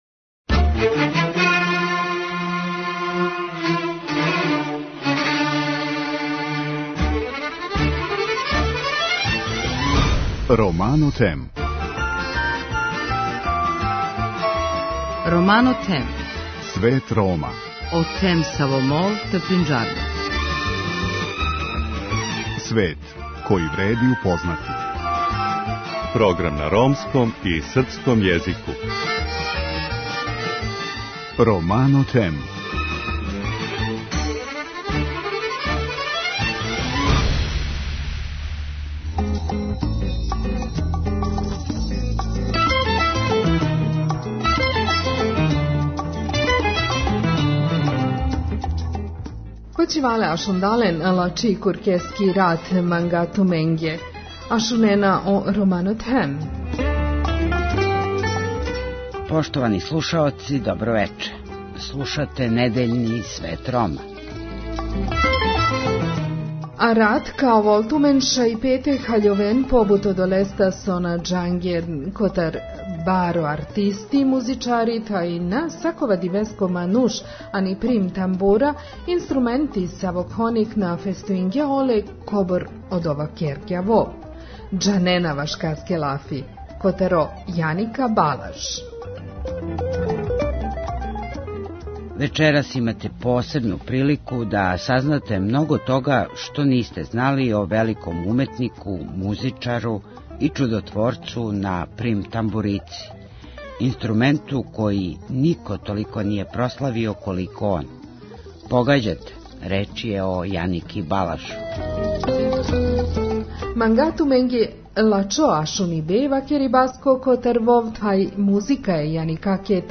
И док маестрално свира све што би човеково срце могло да пожели - од класике, оперета, виртуозних композиција различитих жанрова, до староградске и народне музике које бацају слушаоца у севдах, његово тело и лице су савршено мирни.